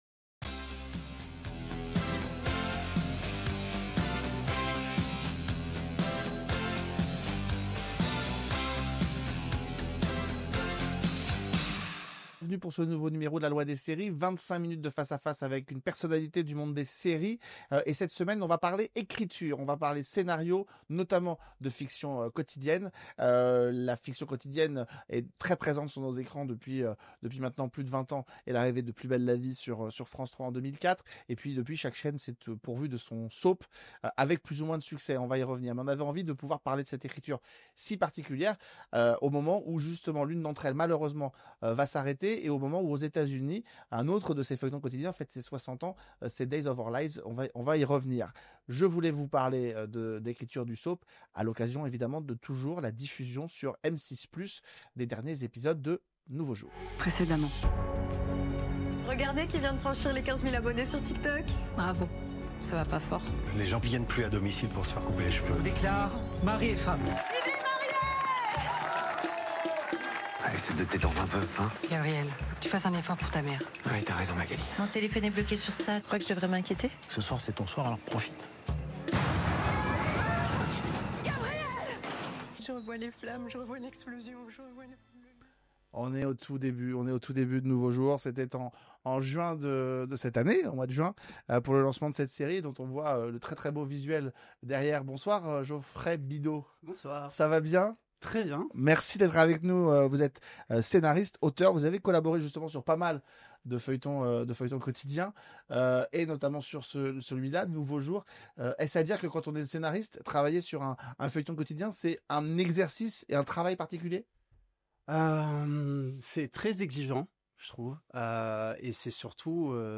L’invité